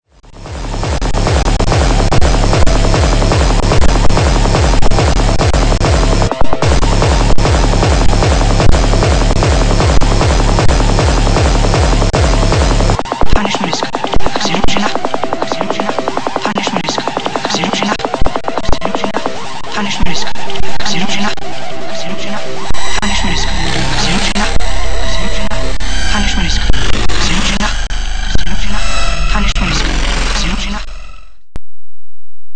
I tried it out on a few different sounds, the result’s are a little longer and they don’t share parameters:
rusty_glitched
Rusty_glitched.wav